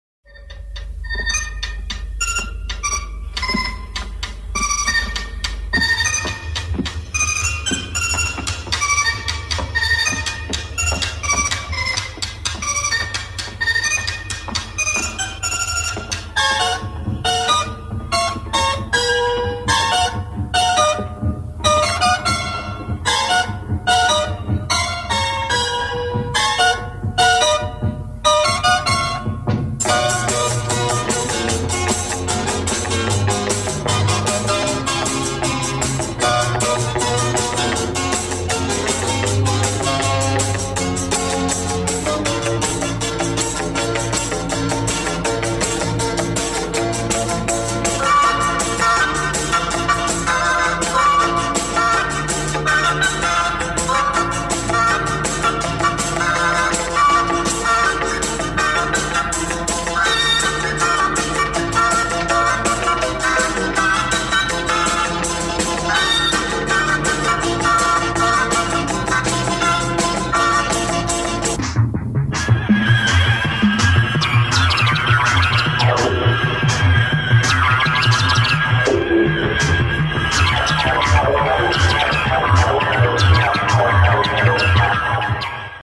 МУЗЫКА ИЗ ФИЛЬМА.
(стерео, 128kbps, 44khz)